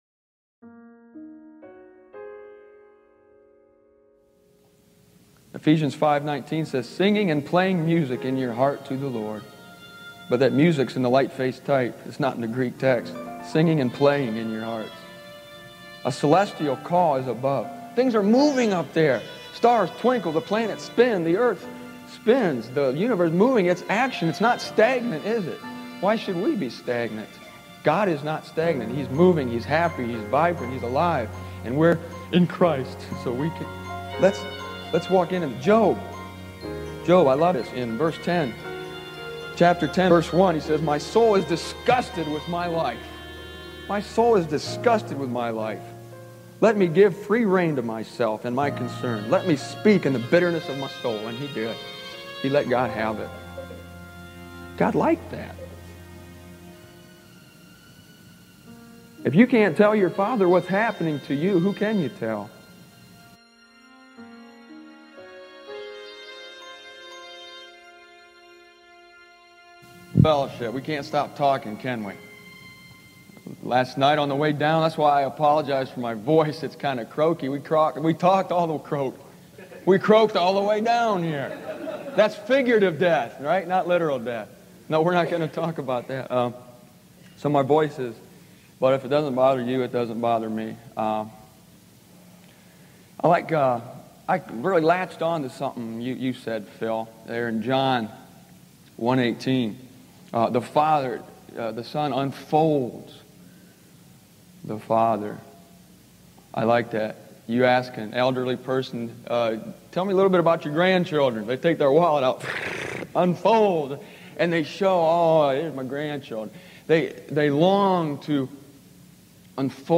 turning a 24 year-old message that I recorded on cassette tape